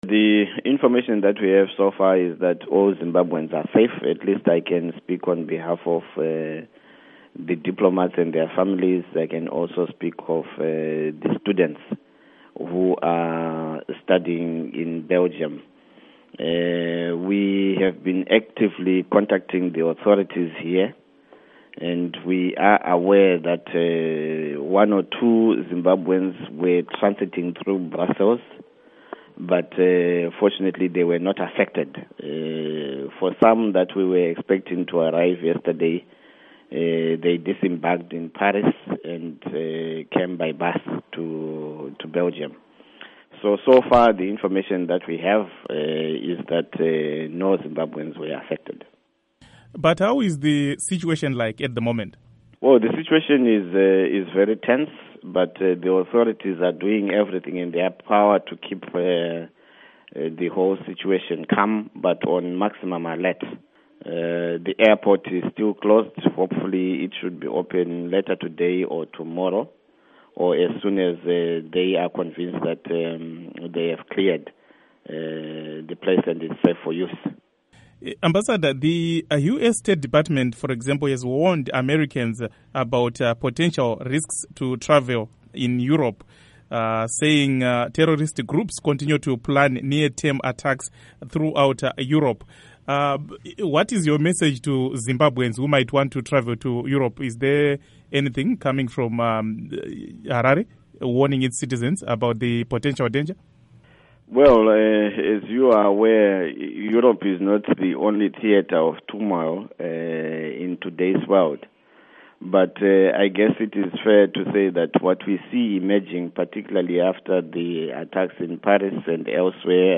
Interview With Ambassador Chifamba on Belgium Bombings